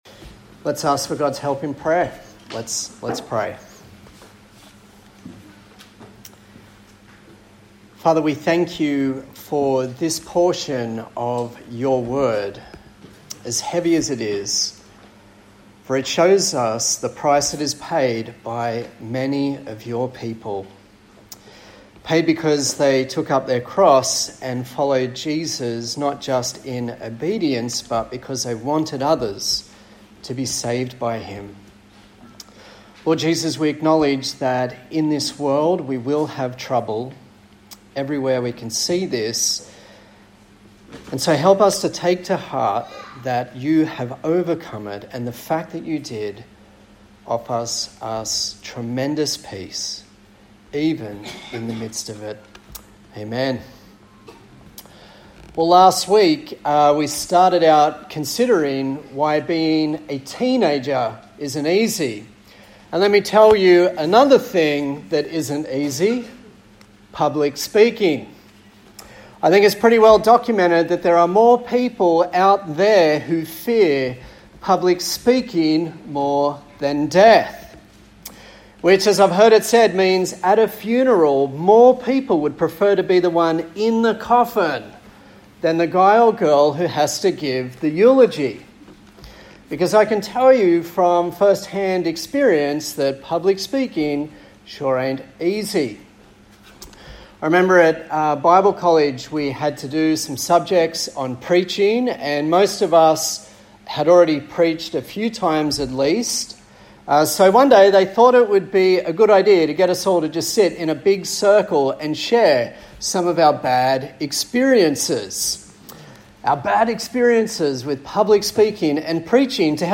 Acts Passage: Acts 6:8-8:3 Service Type: Sunday Morning